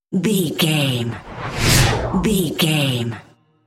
Whoosh fast trailer
Sound Effects
Fast paced
Atonal
Fast
futuristic
intense
whoosh